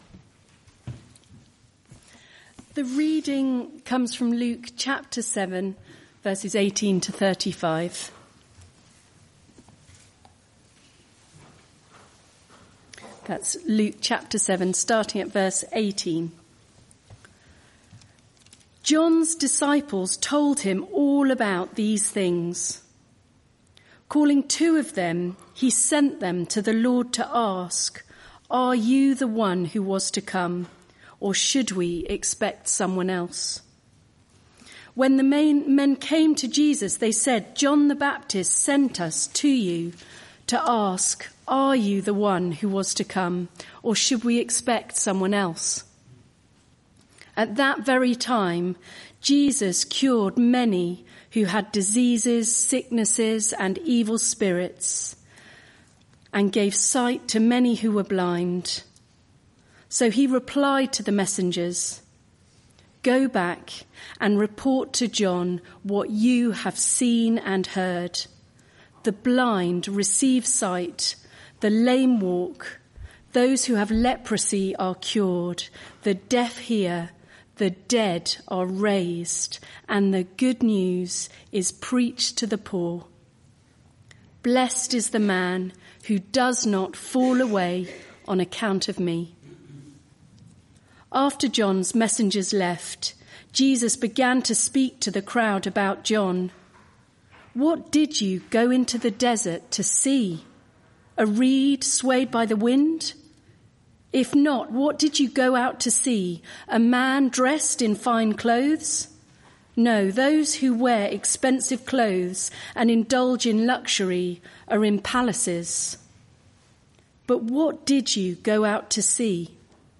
Media for Arborfield Morning Service on Sun 20th Oct 2024 10:00
Theme: Sermon